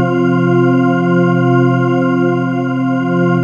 PADY CHORD01.WAV